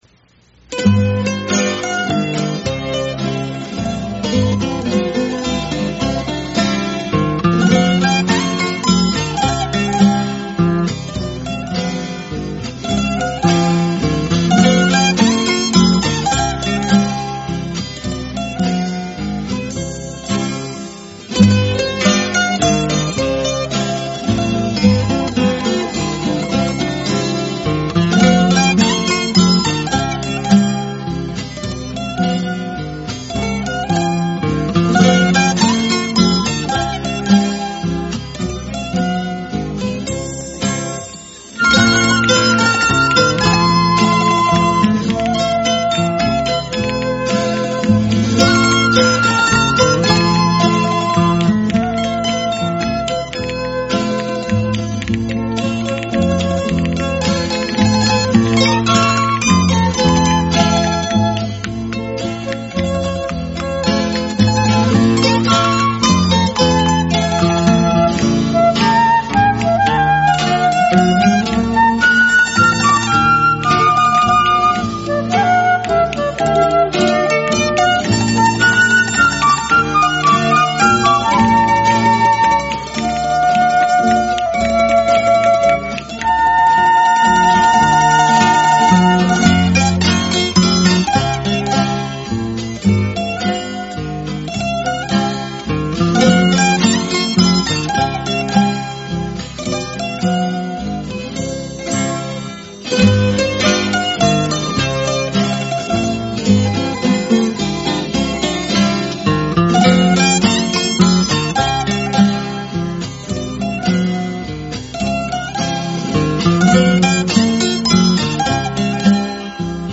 Guabina